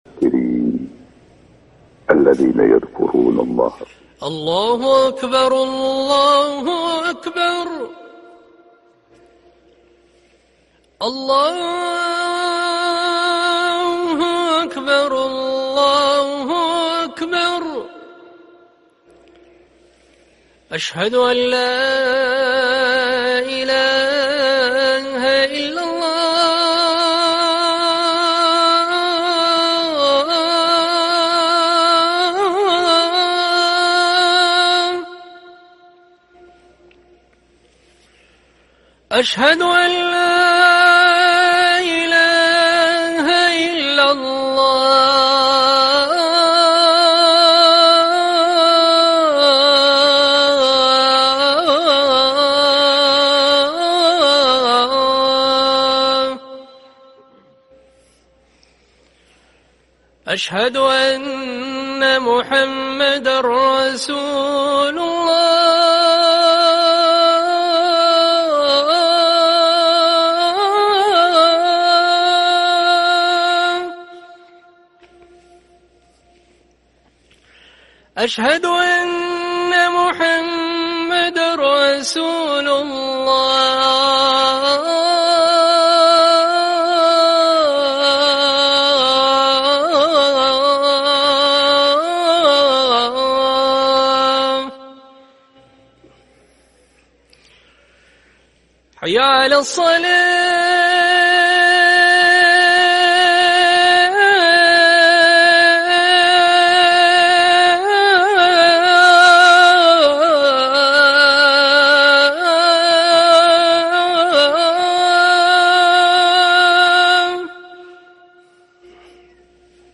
أذان الفجر للمؤذن حمد دغريري الأربعاء 4 صفر 1444هـ > ١٤٤٤ 🕋 > ركن الأذان 🕋 > المزيد - تلاوات الحرمين